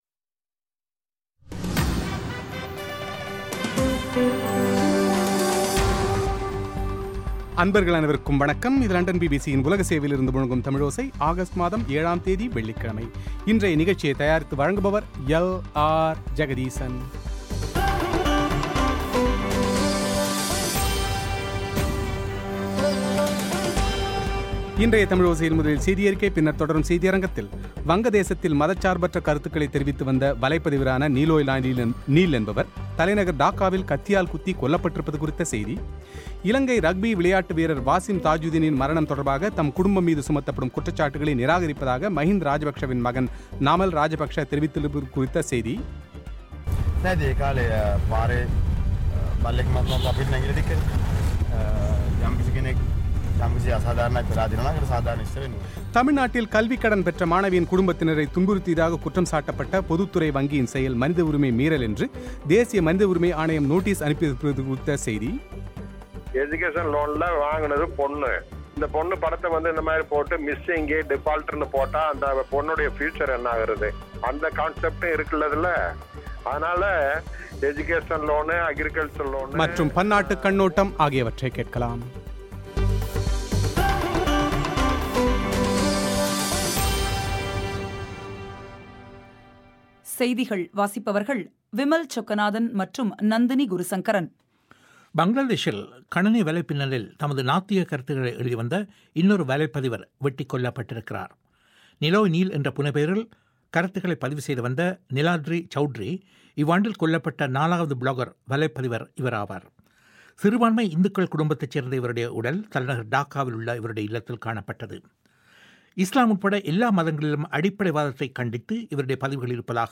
செவ்வி